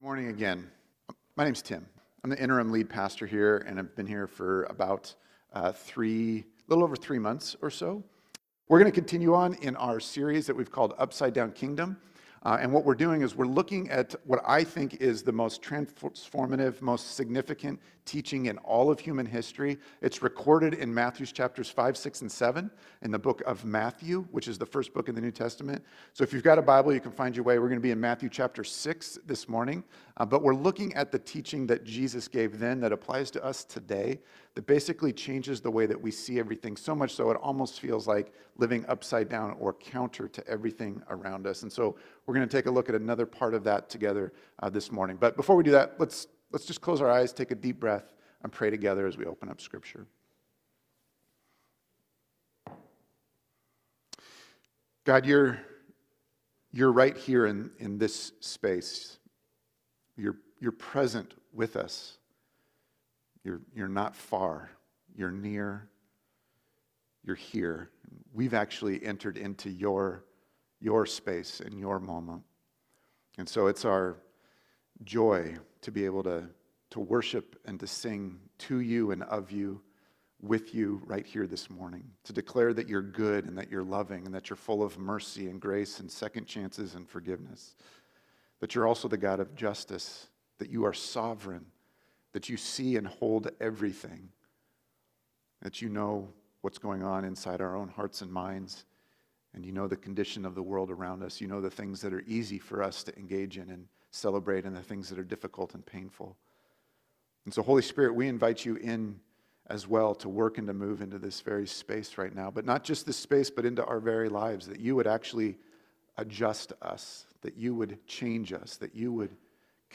Sermons | Missio Community